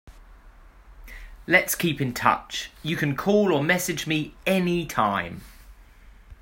音声（イギリス）つきなので、まねして発音してみてください。